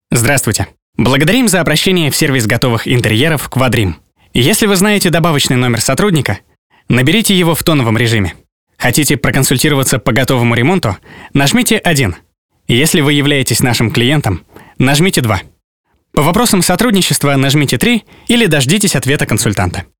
Автоответчик
Муж, Автоответчик
SE X1, Long VoiceMaster, Scarlett 2i2